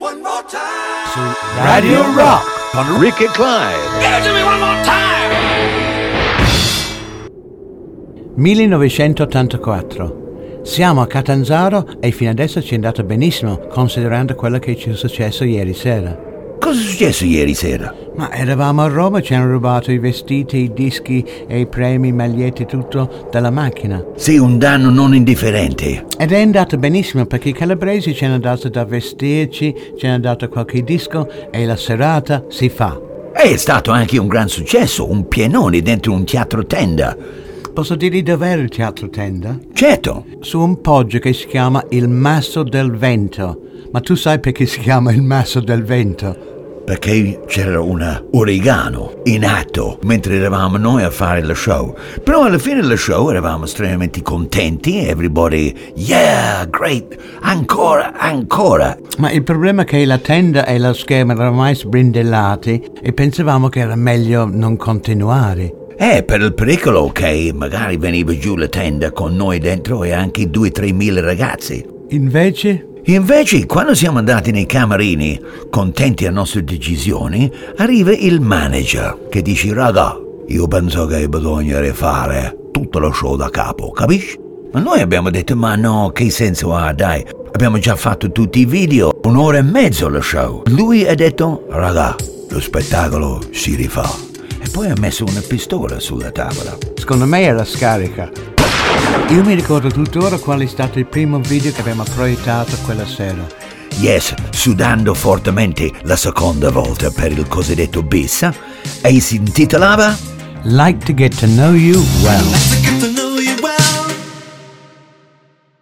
Voci: Rick Hutton, Clive Griffiths.